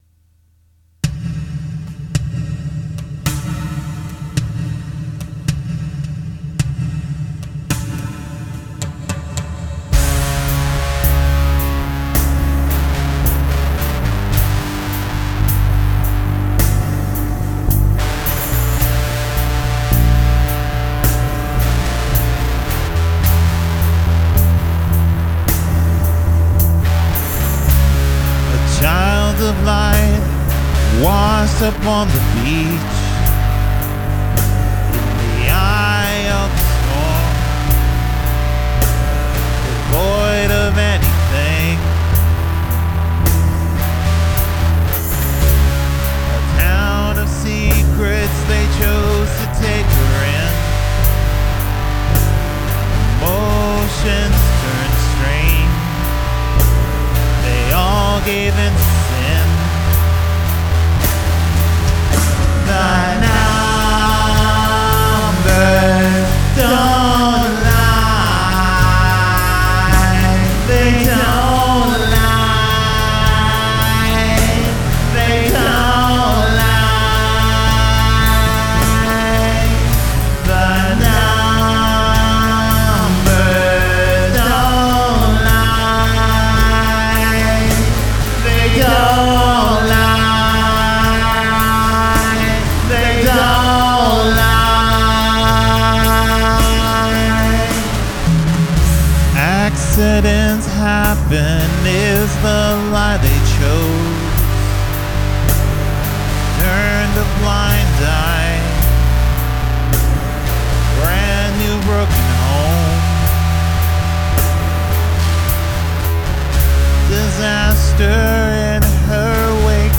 Use the Royal Road chord progression
Menacing bass… nice breakdown section… poor Christina…